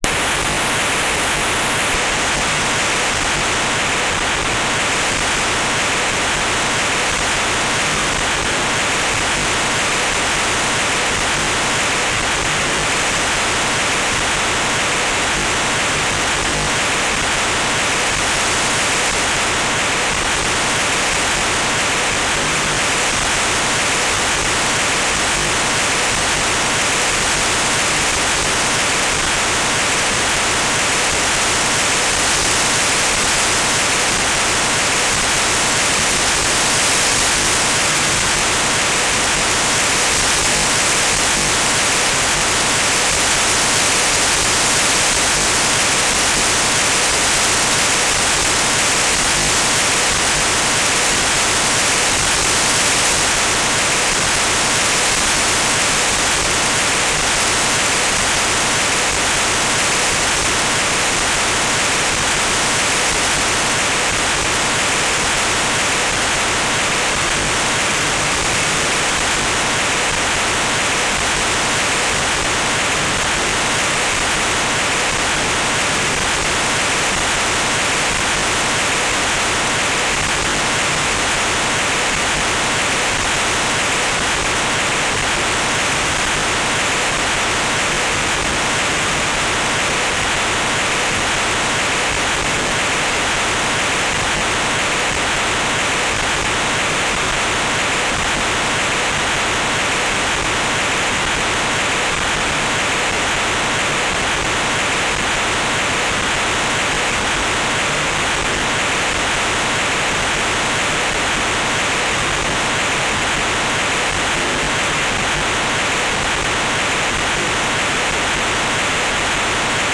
"transmitter_description": "Telemetry",
"transmitter_mode": "FM",